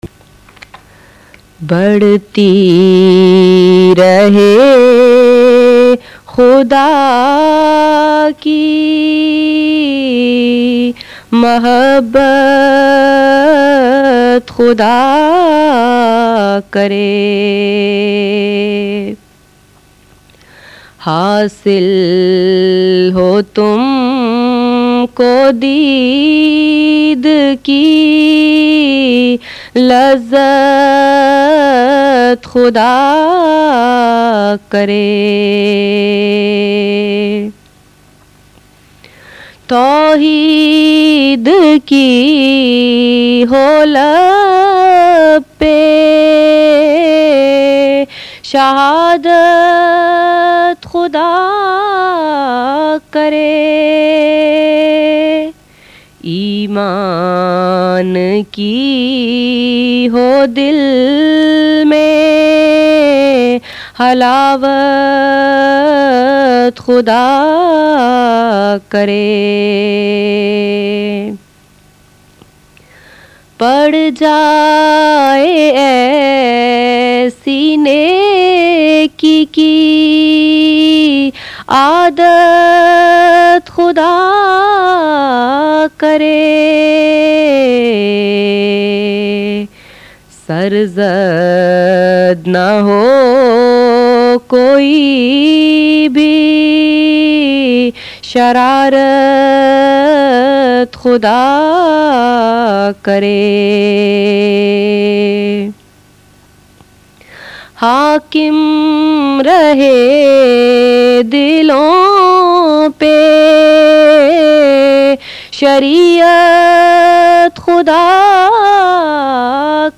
Jalsa Salana Qadian 2005
Voice: Member Lajna Ima`illah